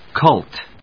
/kˈʌlt(米国英語)/